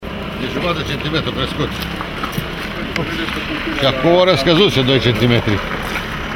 ambianta-cum-masoara-apa.mp3